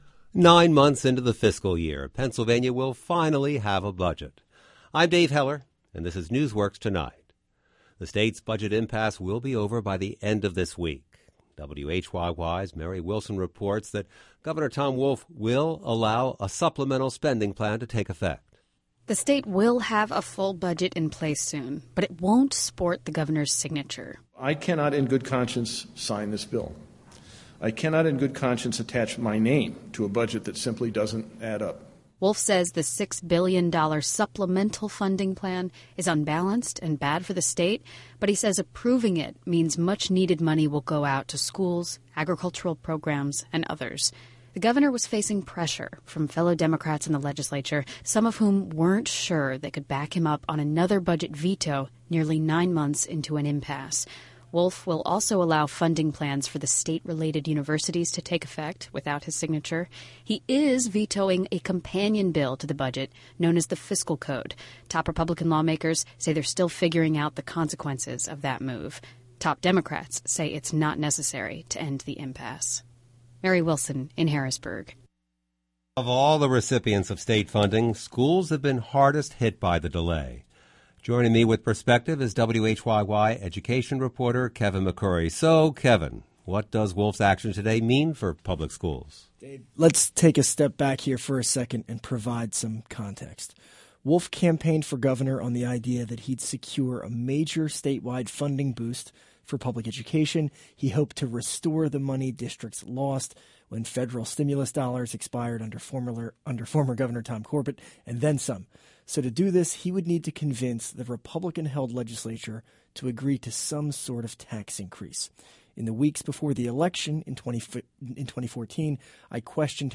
NewsWorks Tonight was a daily radio show and podcast that ran from 2011-2018.